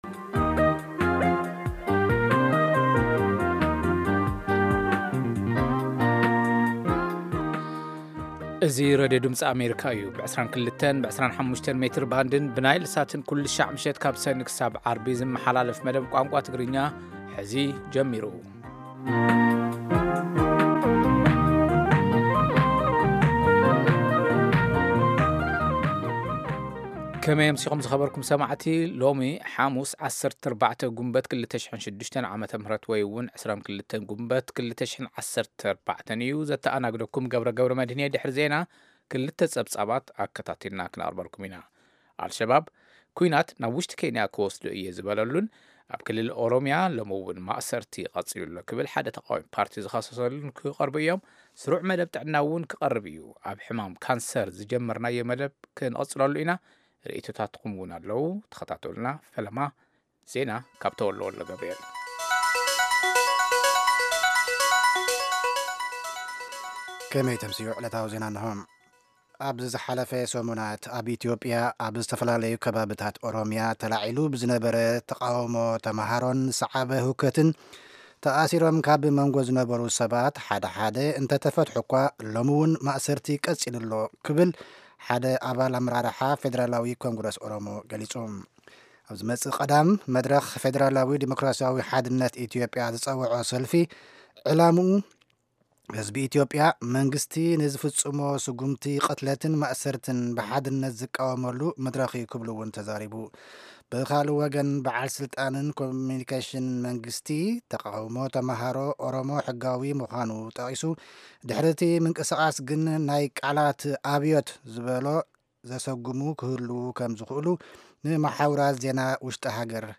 Half-hour broadcasts in Tigrigna of news, interviews with newsmakers, features about culture, health, youth, politics, agriculture, development and sports on Monday through Friday evenings at 10:00 in Ethiopia and Eritrea.